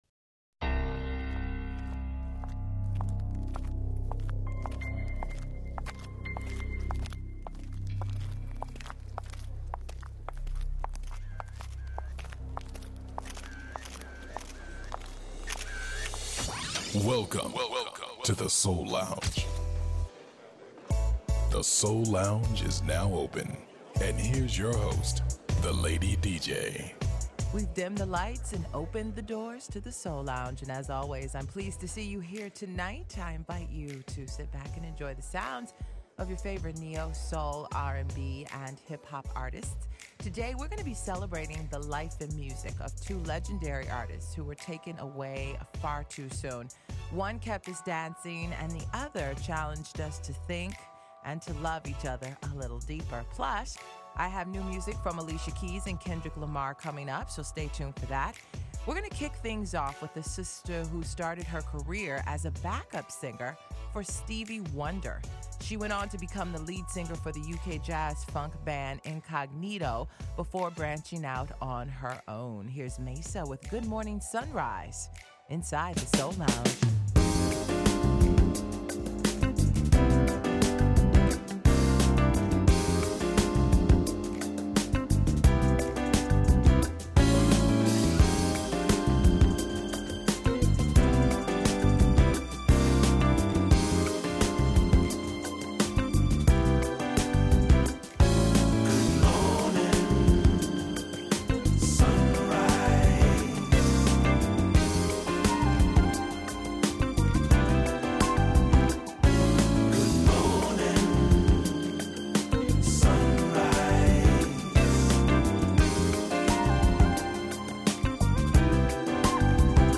You’ll also hear Classic Soul from legendary musicians who have inspired a the new generation of groundbreaking artists.